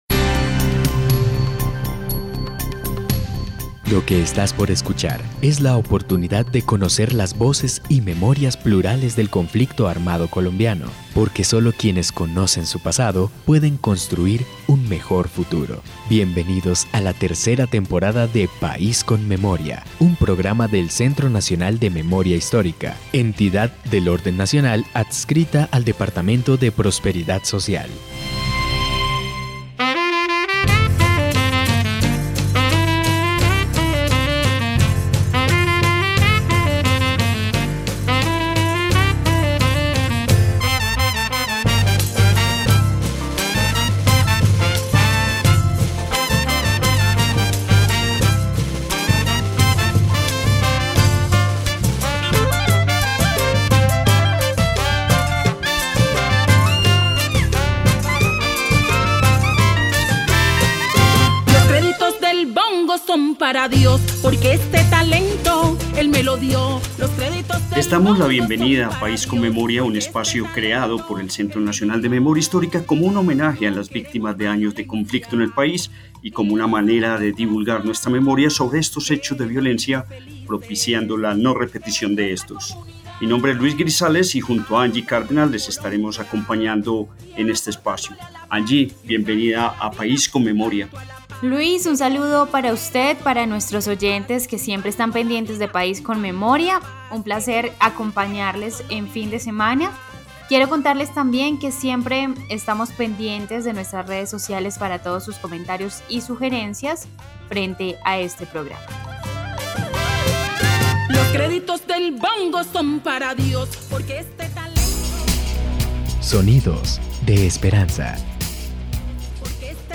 Descripción (dcterms:description) Capítulo número 28 de la tercera temporada de la serie radial "País con Memoria".